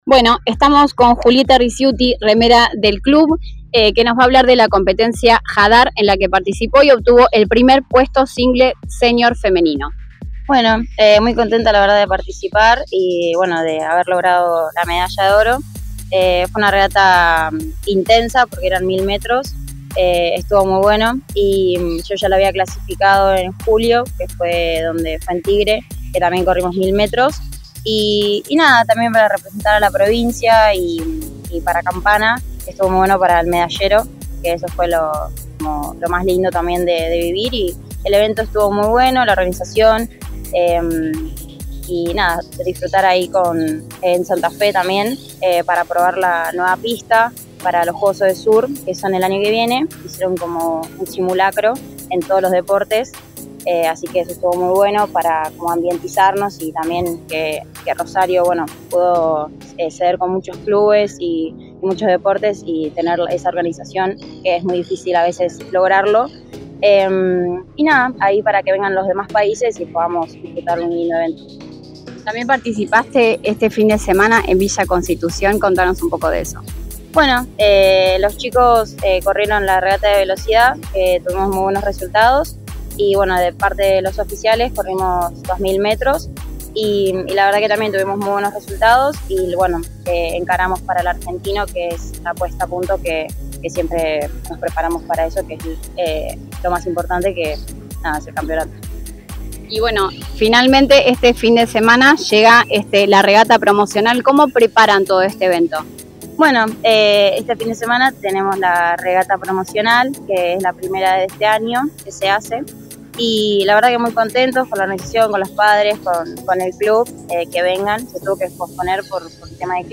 Orgullo CBC: entrevista a la remera